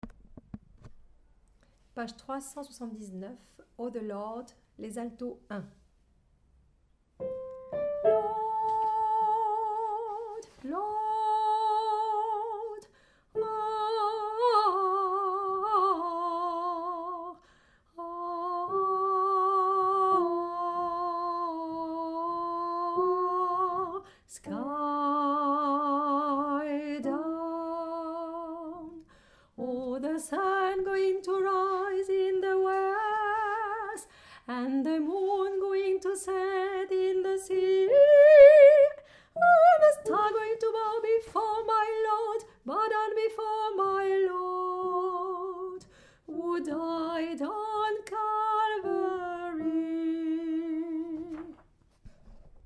Alto1
oh-de-lawd_Alto1.mp3